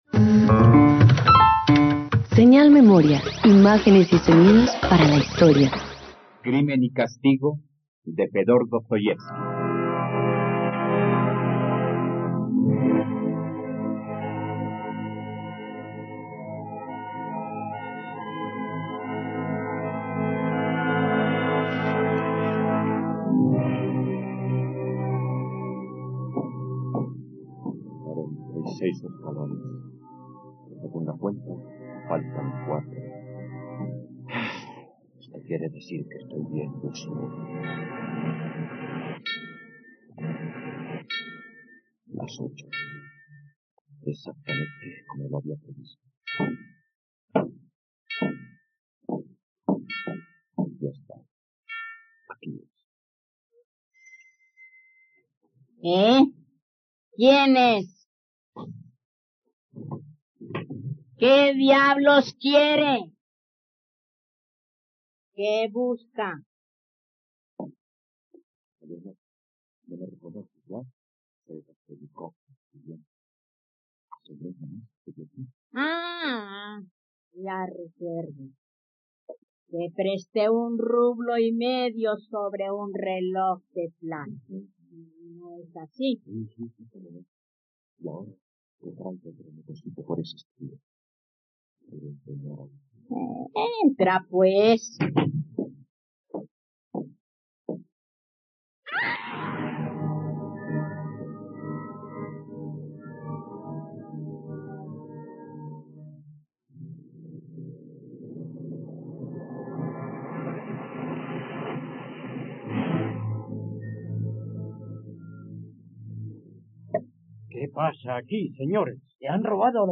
Crimen y castigo - Radioteatro dominical | RTVCPlay
..Radioteatro. Escucha la adaptación radiofónica de la obra ‘Crimen y castigo’ del escritor ruso Fiódor Dostoyevski.